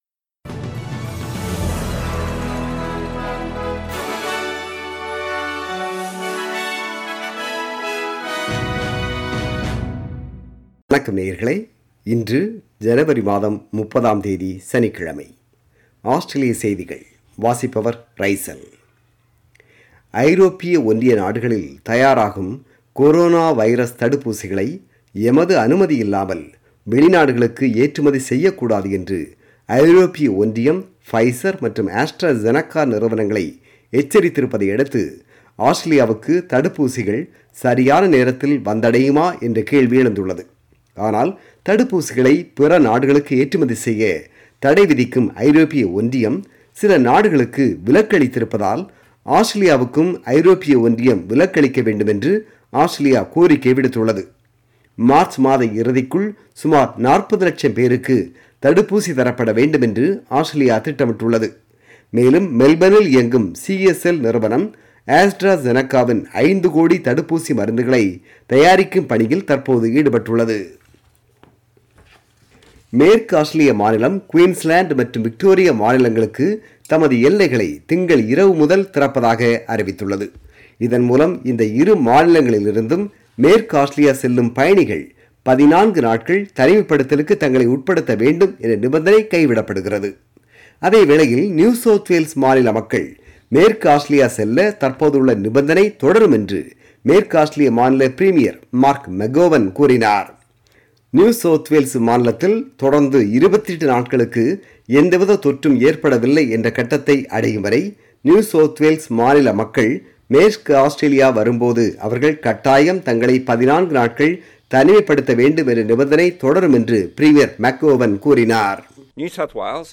Australian News: 30 January 2021 – Saturday